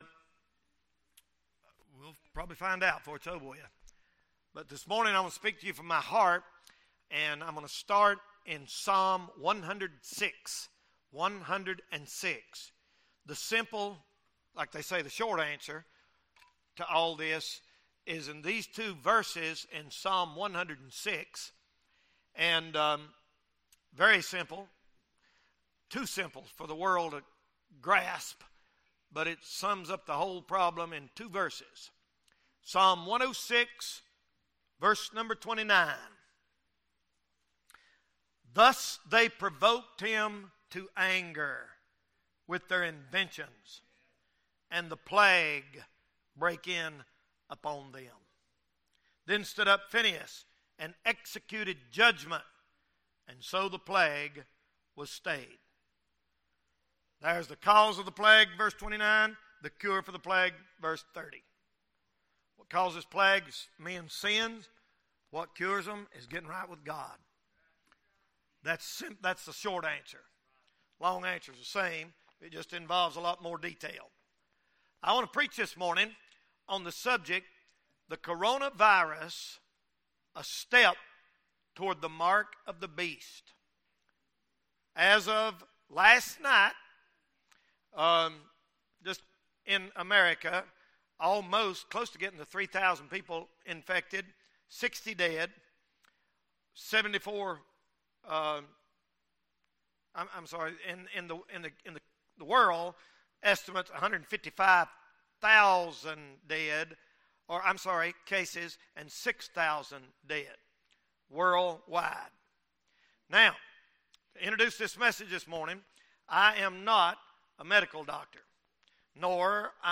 Genre Sermon or written equivalent